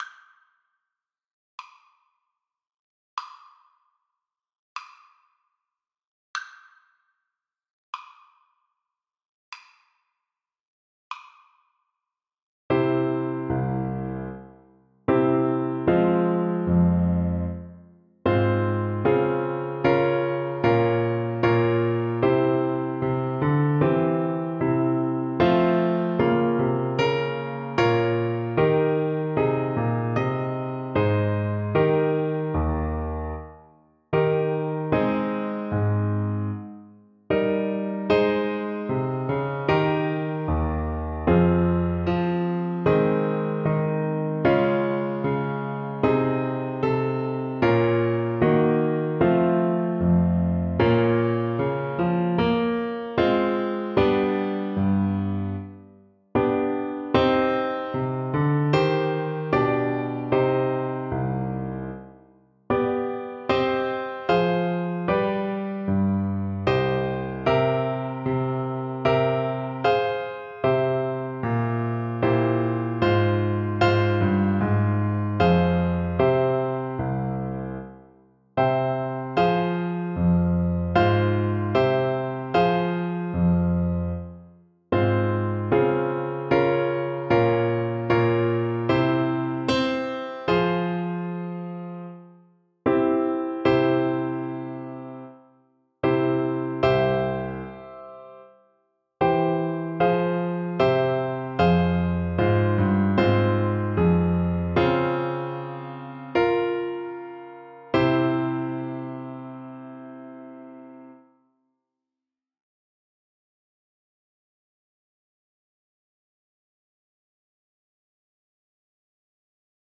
Bassoon version
Andante = c.60
4/4 (View more 4/4 Music)
Classical (View more Classical Bassoon Music)